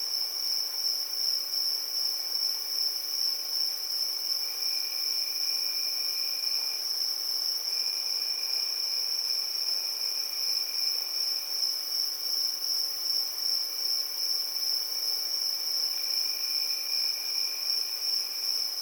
Summer Night Loop.ogg